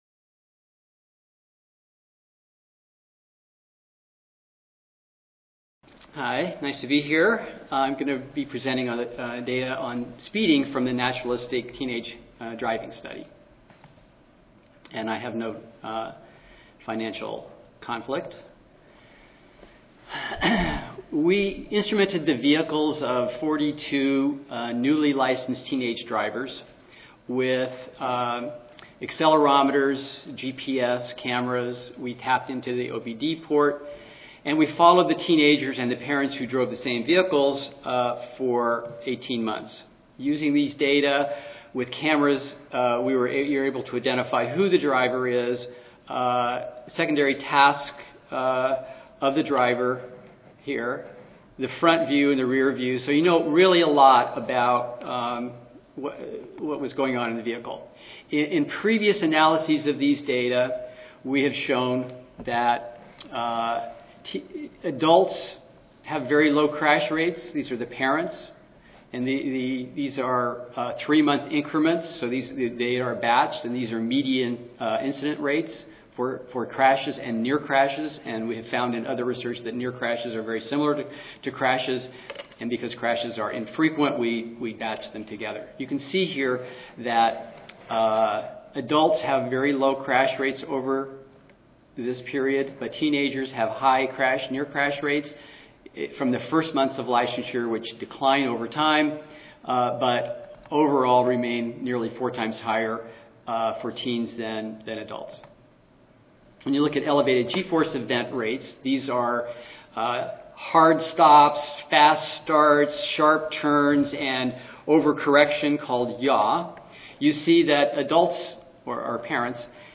4046.0 Transportation-related injury 1 Tuesday, November 1, 2011: 8:30 AM Oral Session Objectives: Identify factors associated with speeding among the novice young studied.